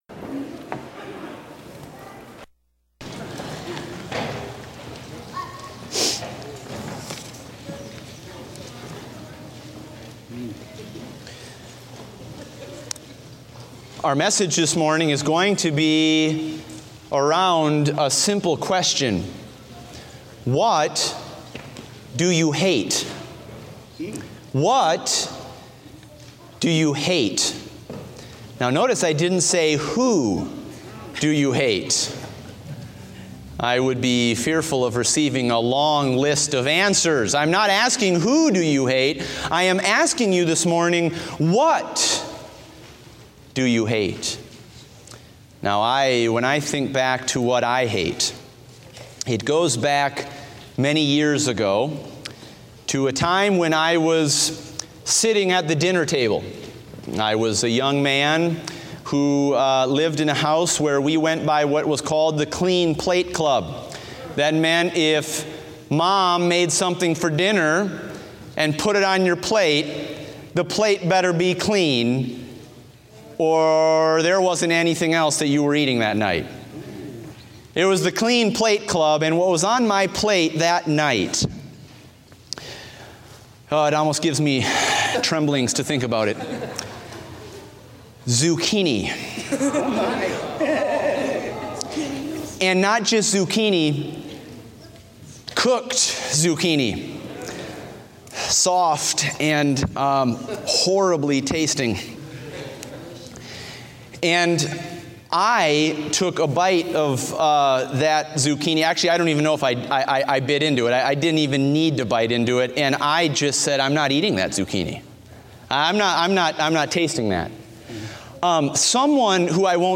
Date: October 23, 2016 (Morning Service)